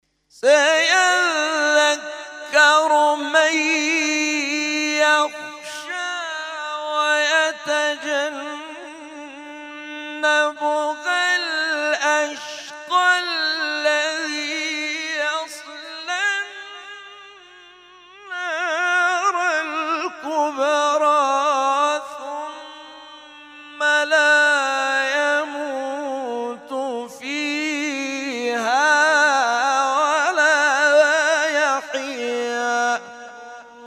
محفل انس با قرآن در آستان عبدالعظیم(ع)
قطعات تلاوت